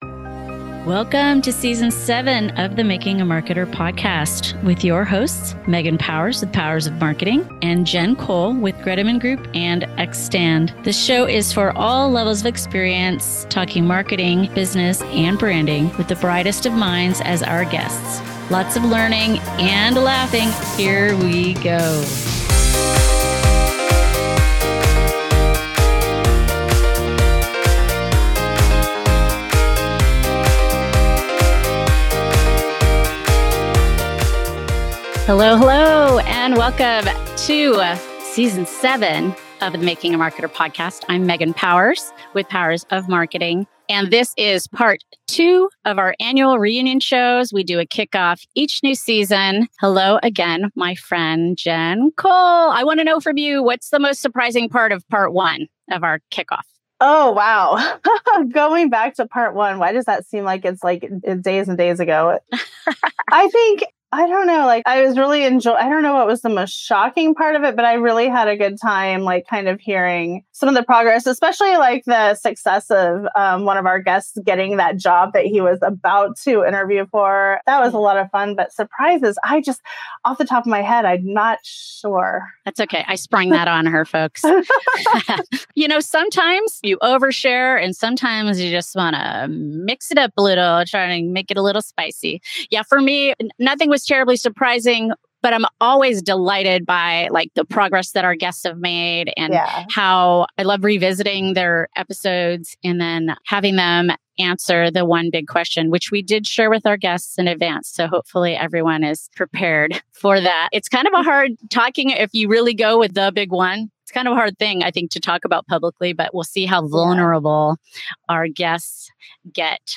Part two of the reunion show with four season six guests is here!